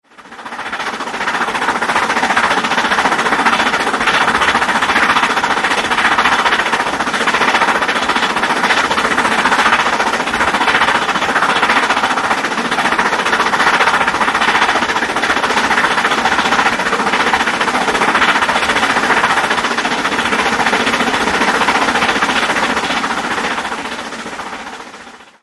This fourth volume of tracks are all on-train recordings.
I seem to recall that, on this occasion we were more than usually troubled by noisy kids!
And the noise is tremendous!